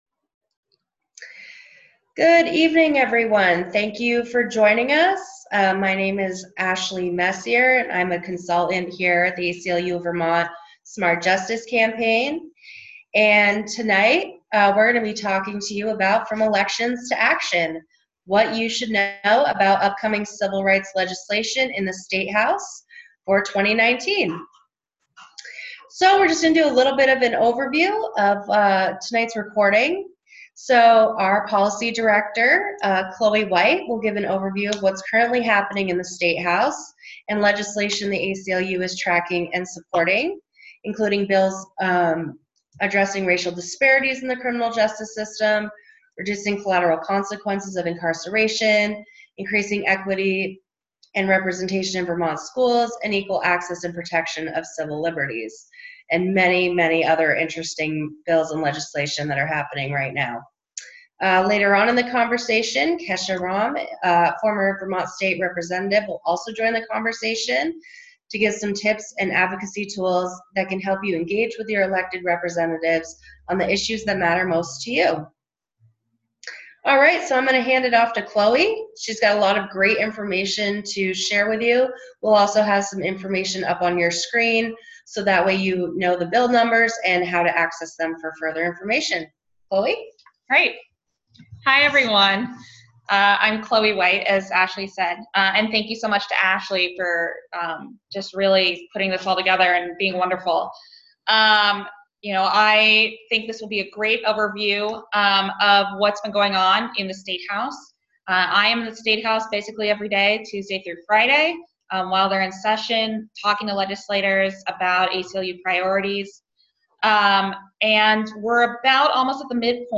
From Elections to Action: A recorded conversation about current legislation - ACLU of Vermont
Kesha Ram, Former Vermont State Representative, joins the conversation to give some tips and advocacy tools that can help you engage with your elected representatives on the issues that matter most to you.